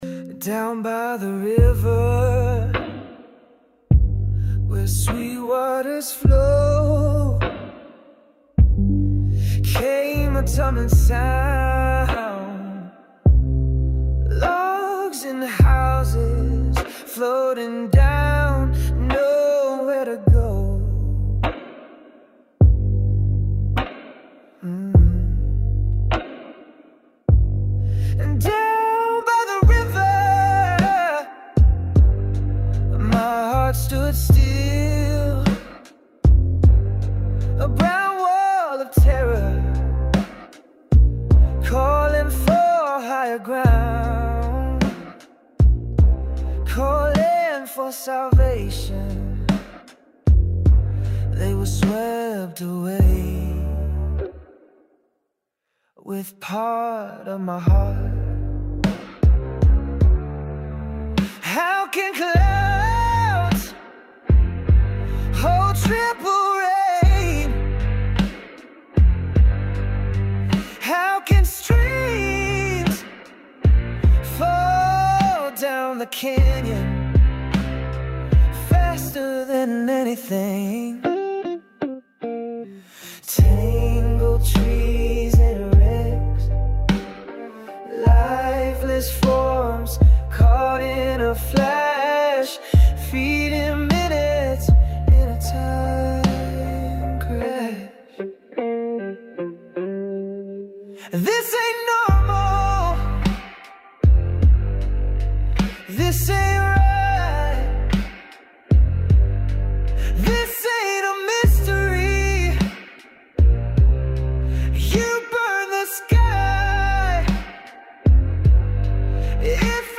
AI music
in either Blues
AI_DownByTheRiver_Blues.mp3